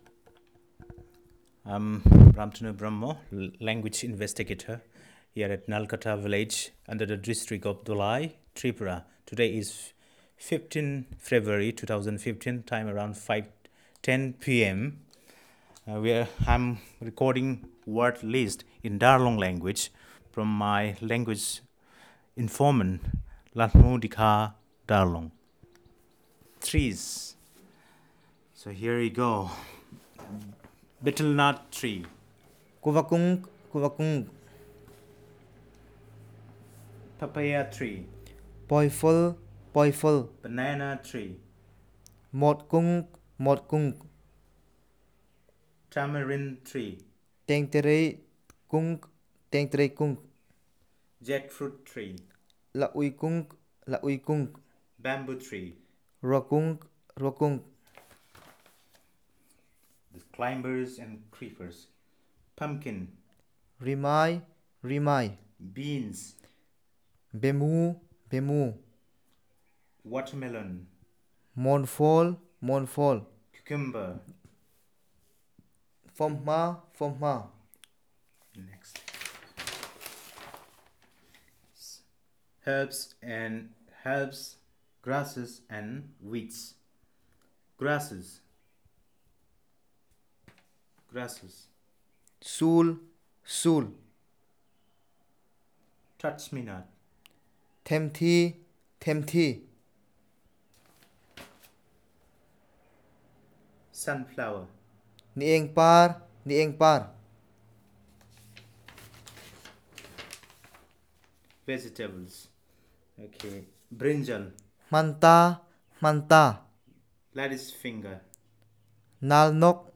Elicitation of words about trees, creepers, flowers, fruits and vegetables